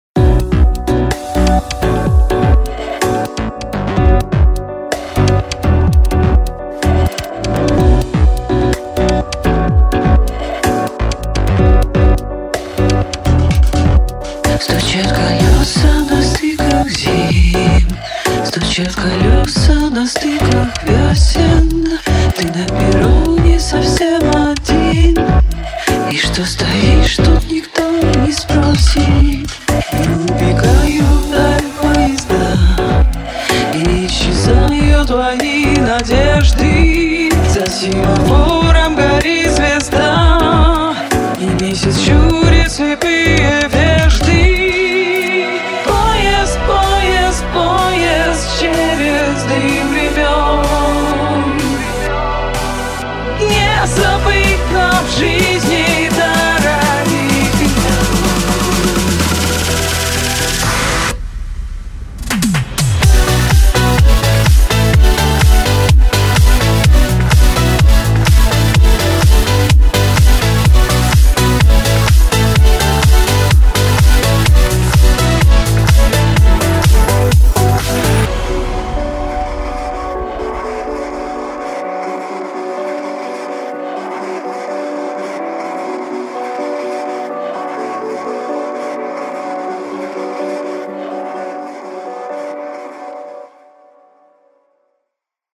концовка с прямой бочкой для примера
И шансон можно раскачать )